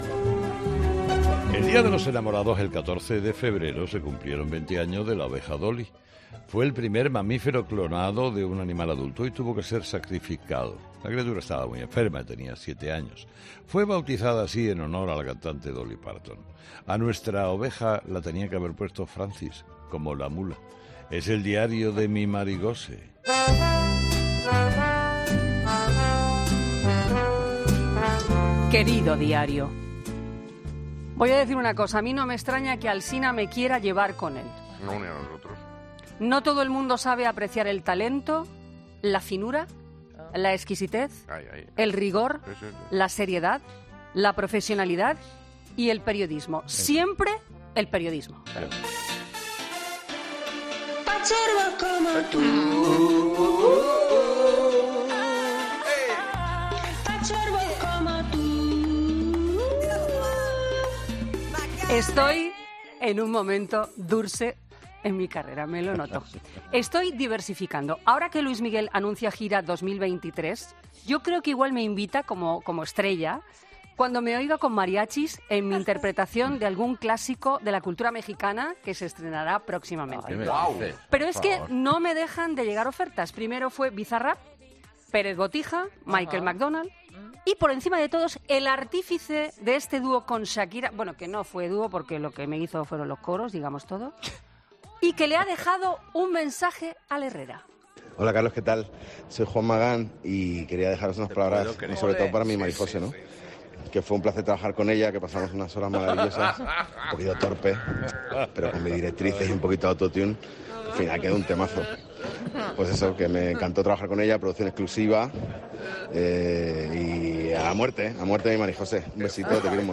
Con un tono de humor, aseguraba que fue "un poquito torpe", pero que gracias a las "directrices" de Magán y "un poquito de autotune" finalmente ha salido "un temazo".
El mensaje que un cantante y productor español ha mandado a Carlos Herrera